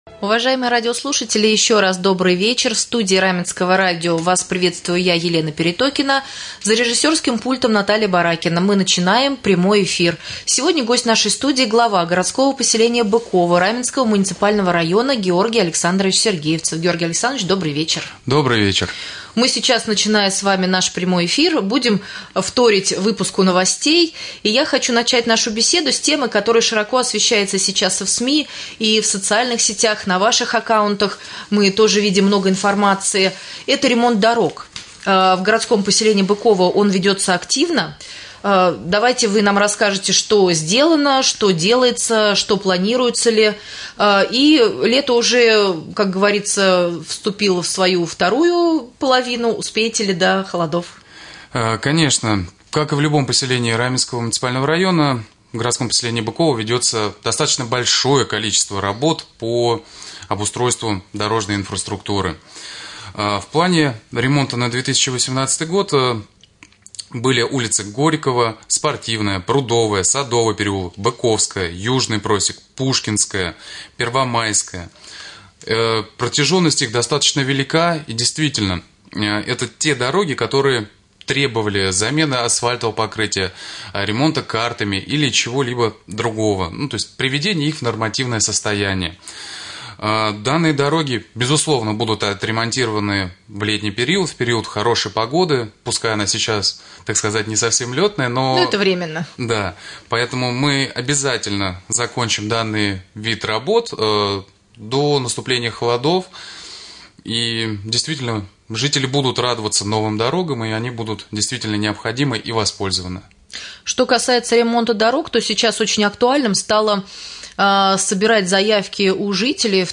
Глава г.п.Быково Георгий Сергеевцев стал гостем прямого эфира на Раменском радио